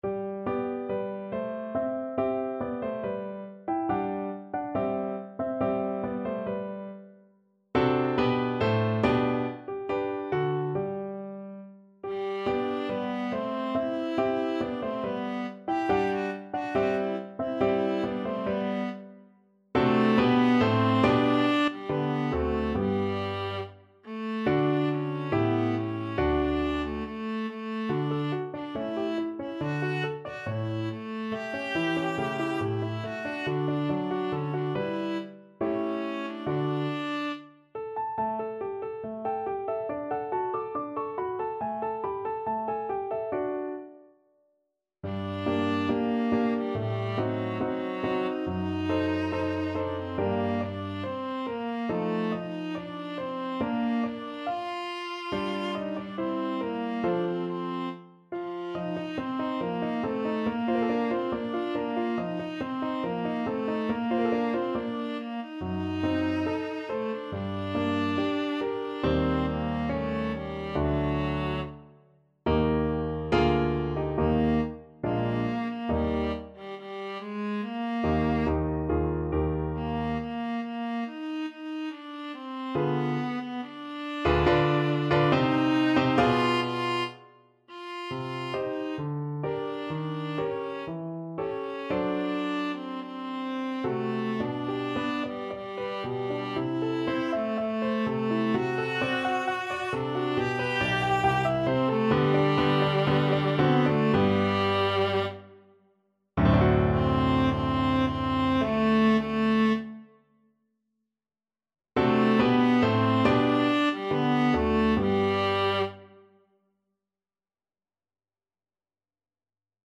Free Sheet music for Viola
2/4 (View more 2/4 Music)
G major (Sounding Pitch) (View more G major Music for Viola )
= 70 Allegretto
Classical (View more Classical Viola Music)